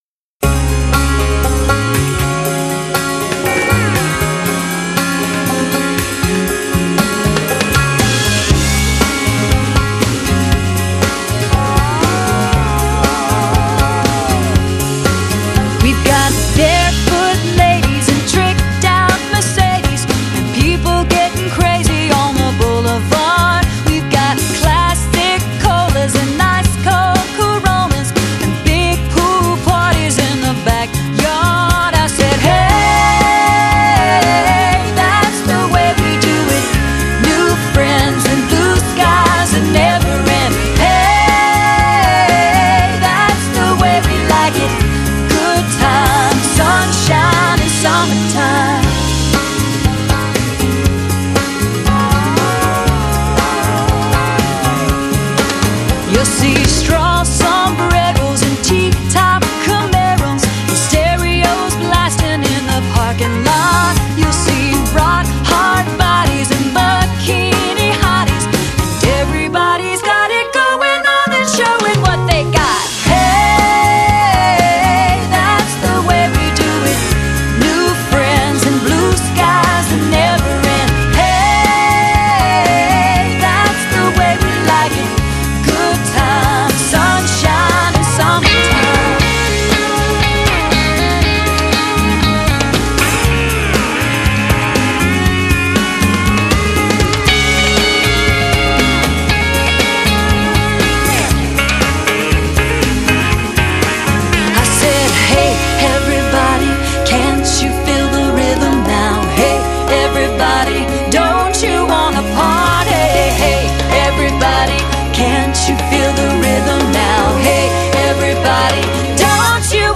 她的嗓音异常优美，甜美的乐感能抓住每个人的心灵。